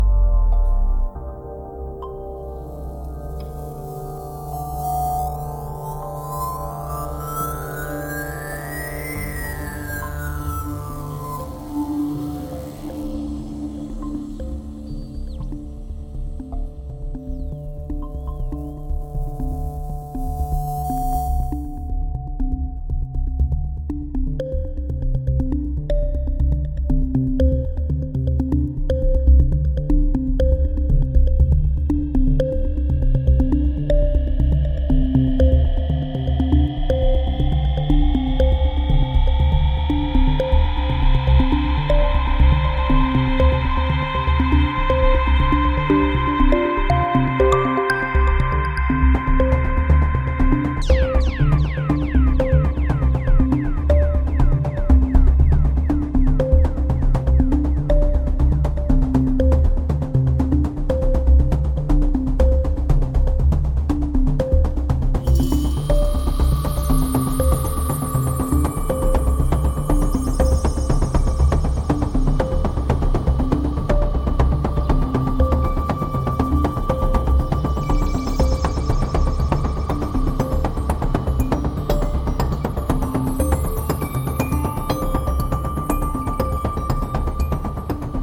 wide-screen ambient version
Electro House Techno